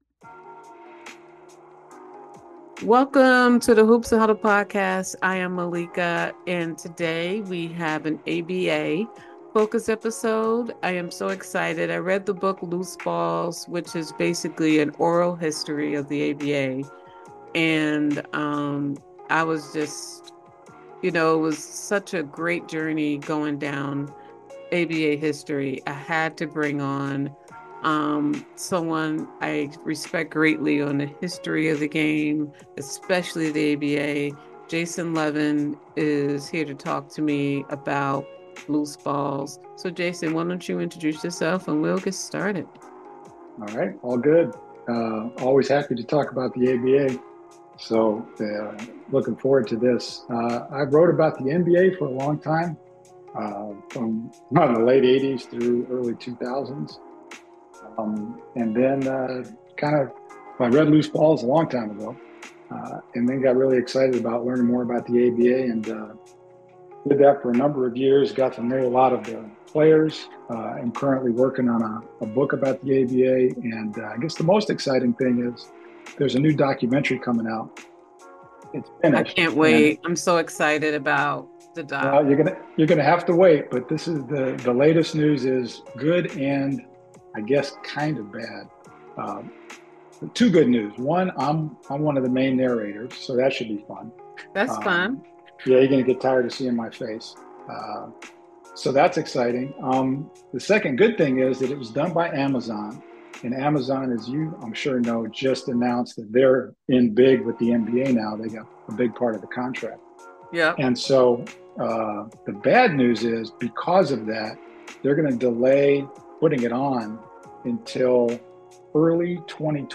The conversation concludes with announcing the documentary's release in early 2026 and the ongoing efforts to compensate ABA players.